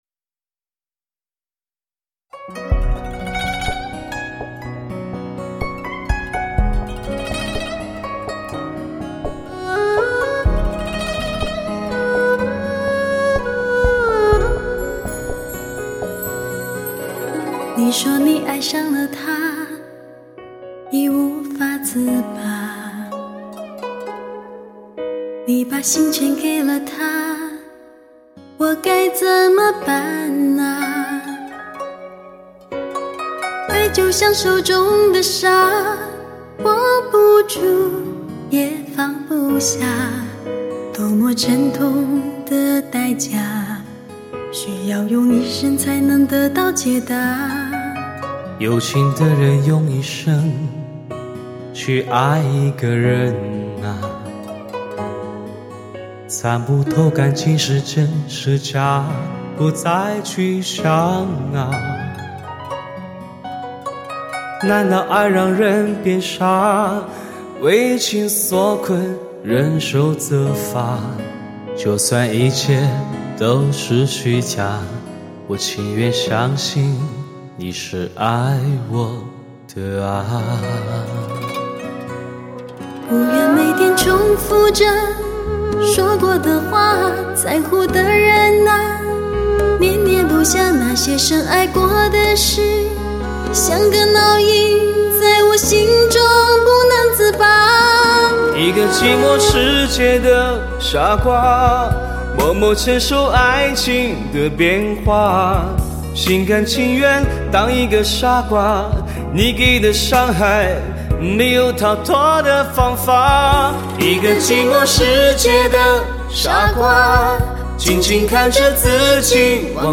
钻石级靓声天碟，情歌精选集。
15首唯美浪漫的对唱情歌，靓声靓歌尽在不言中，最具人气HI-FI对唱组合完美结合，
15首对唱情歌演绎男女间的情感世界--“体验真爱感动满载，有情天地，逐首细味！